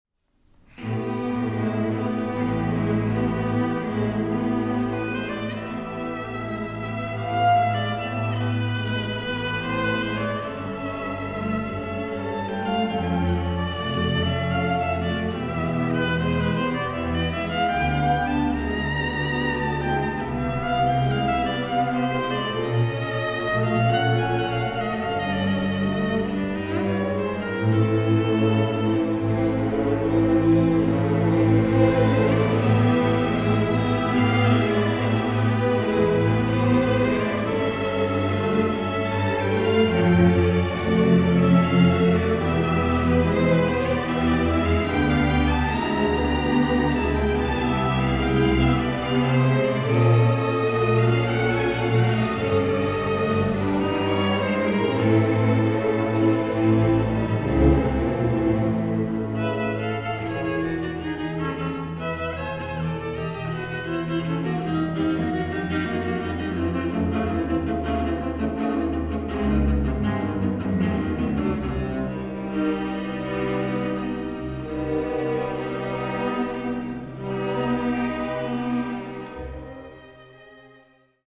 for viola solo and string orchestra